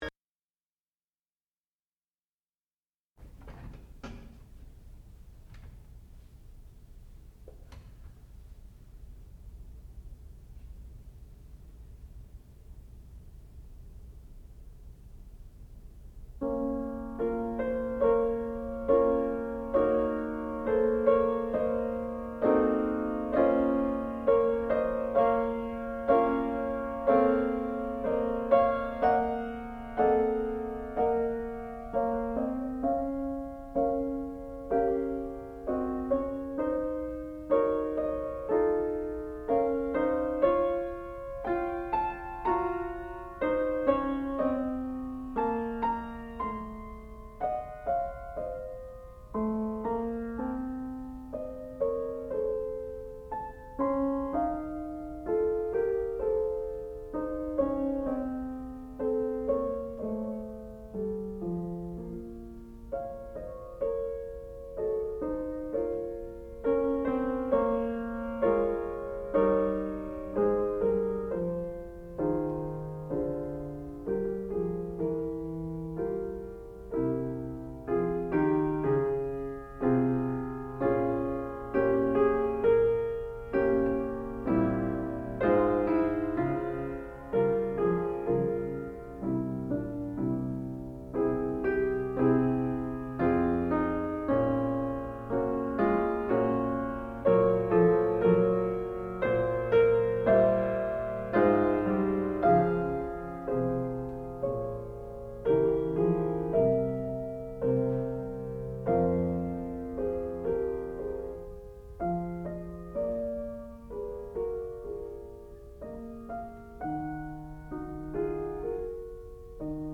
sound recording-musical
classical music
piano
Master Recital